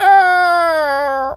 seagul_squawk_hurt_05.wav